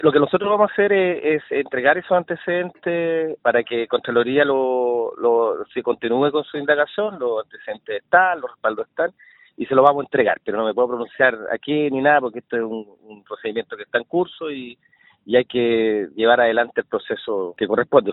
En conversación con Radio Bío Bío, el representante del Gobierno en la zona se limitó a indicar que reunirán los antecedentes para responder lo constatado por el organismo contralor, evitando profundizar en el tema ni precisar cuál fue el motivo de su ida a Temuco.